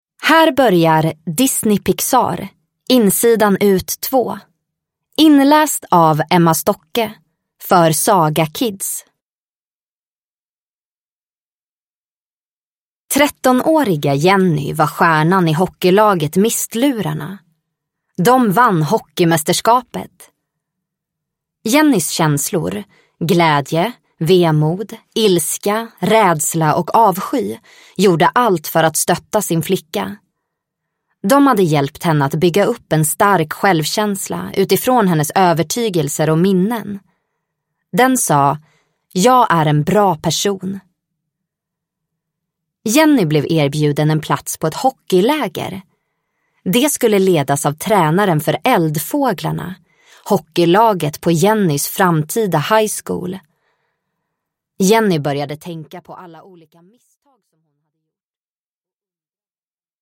Insidan ut 2 – Ljudbok